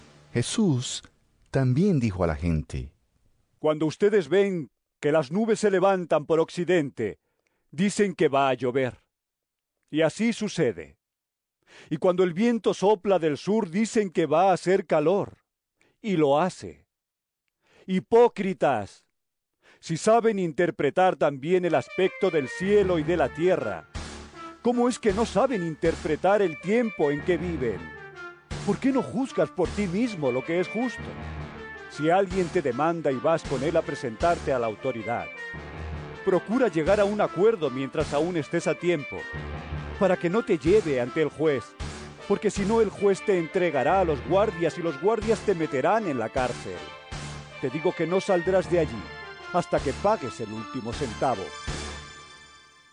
Lc 12 54-59 EVANGELIO EN AUDIO